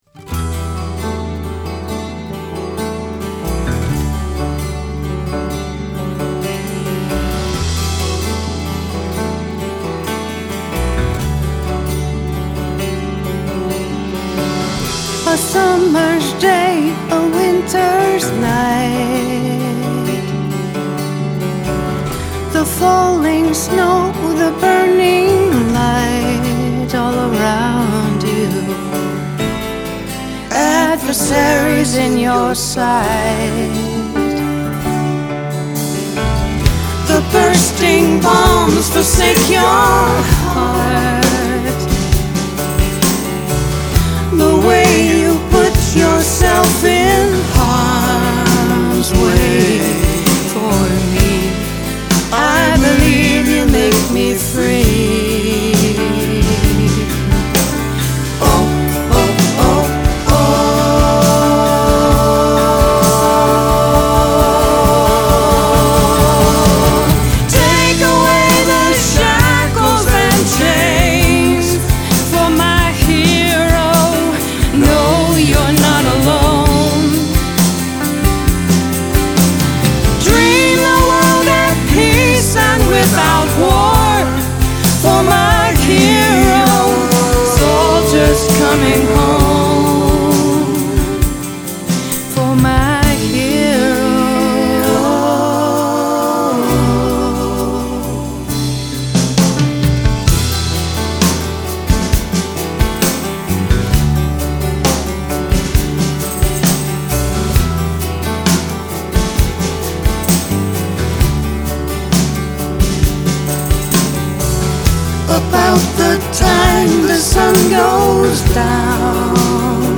Piano/Vocals
Guitar/Bass/Drums
Backing Vocals